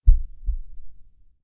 ACE3 / addons / medical / sounds / heart_beats / slow_1.wav